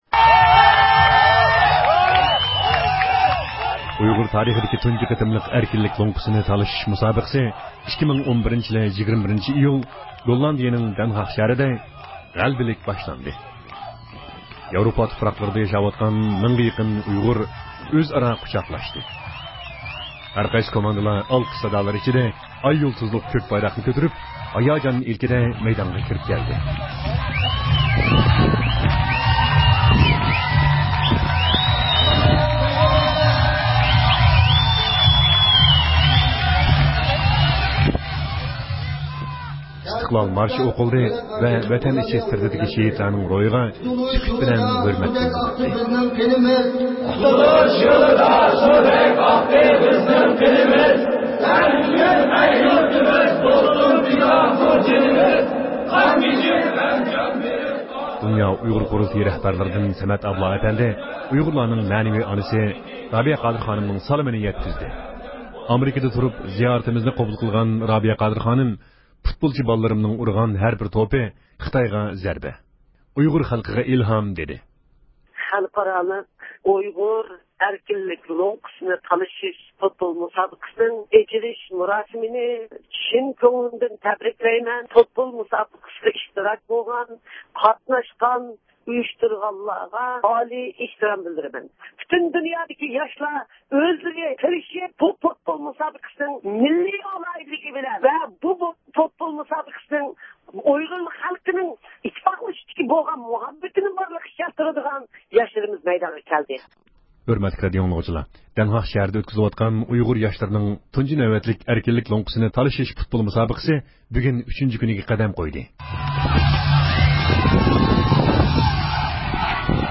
بىز بۇ ئارىدا مۇسابىقە مەيدانىدىكى ئەزىمەتلەر، مەشقاۋۇل ۋە ھەۋەسكارلارنى تېلېفون ئارقىلىق زىيارەت قىلدۇق.